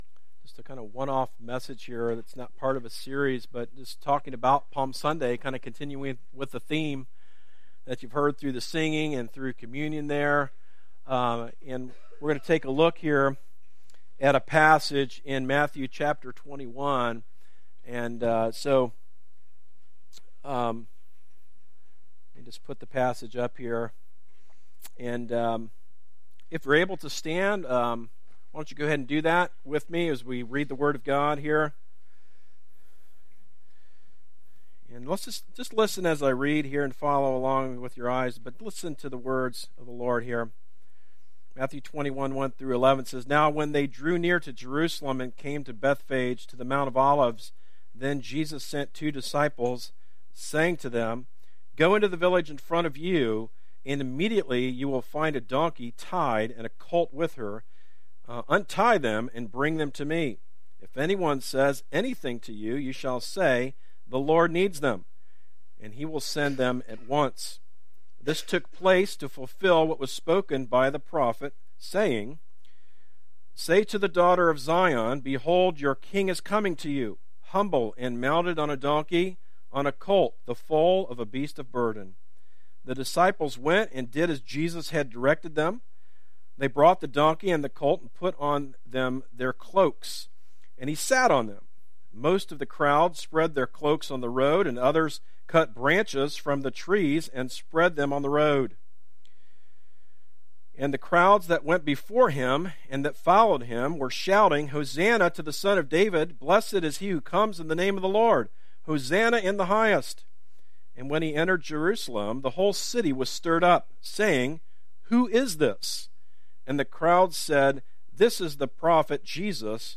From Series: "Stand Alone Sermons"
Sermons that are not part of a series